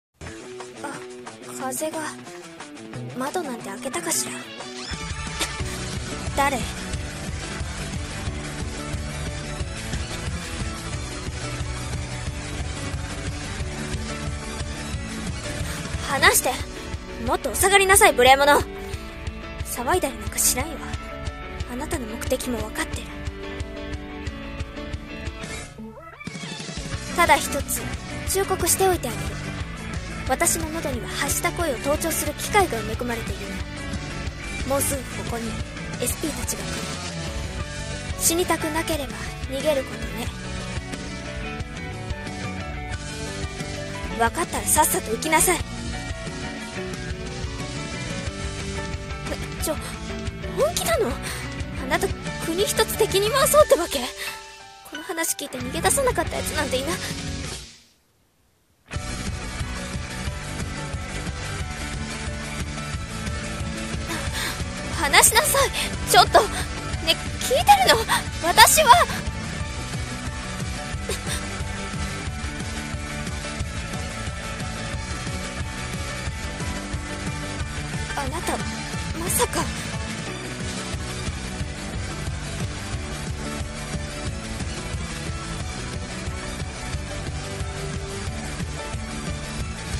【声劇】怪盗とお嬢様(コラボ用)